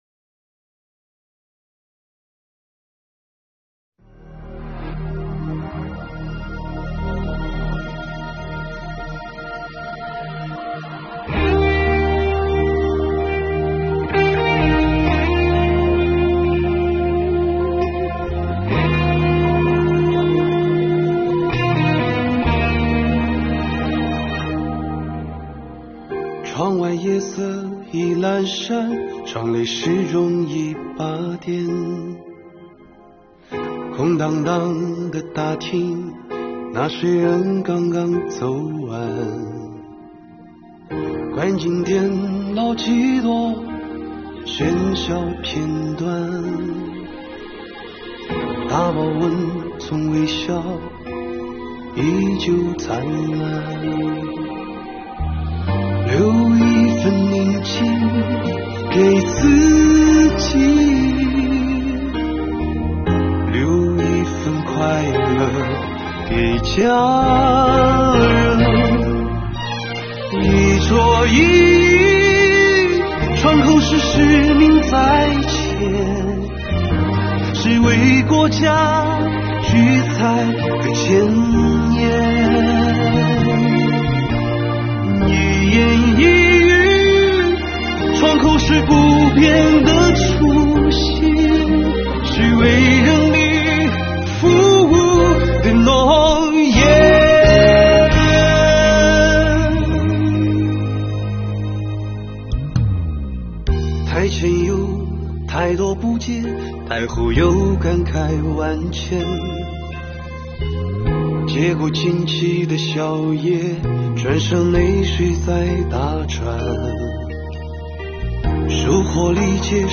2022年新年伊始，我们用税务人录制的歌曲，为大家制作了一本音乐挂历，并将全年办税时间做了标注，伴着歌声开启新征程吧。